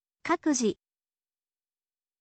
kakuji